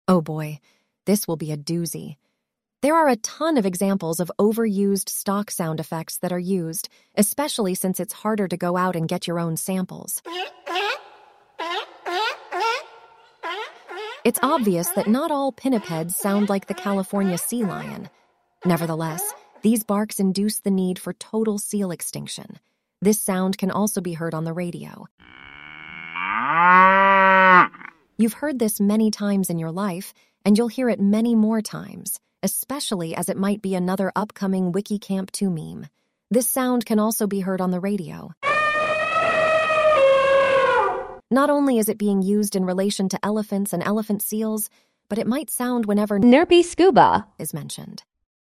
ElevenLabs essentially creates AI voice clips.
ElevenLabs_reads_Trope_excerpt.mp3